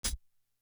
Closed Hats
Electric Chair Hat.wav